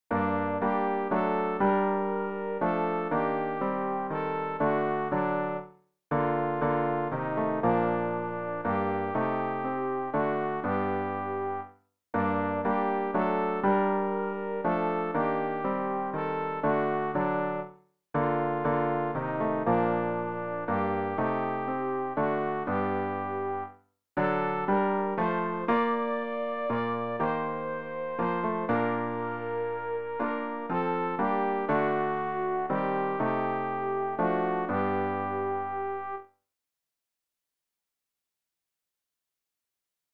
rg-715-mein-gott-wei-bist-du-so-verborgen-sopran.mp3